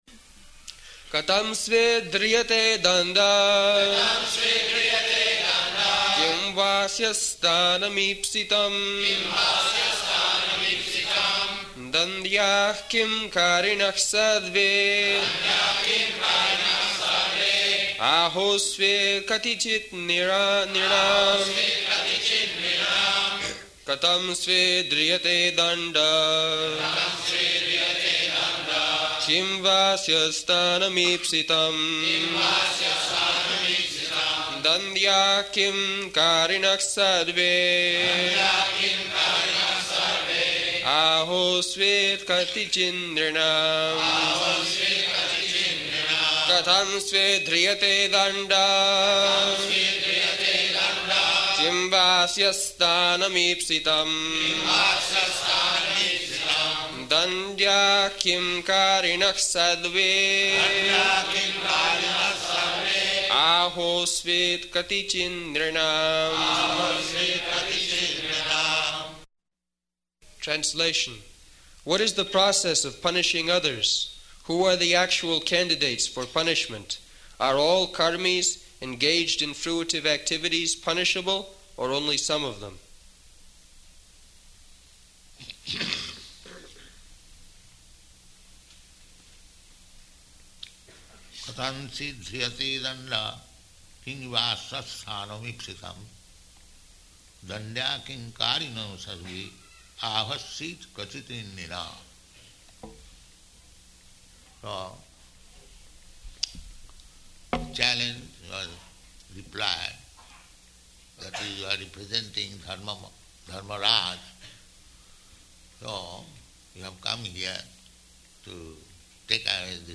Location: Los Angeles
[leads devotees in chanting]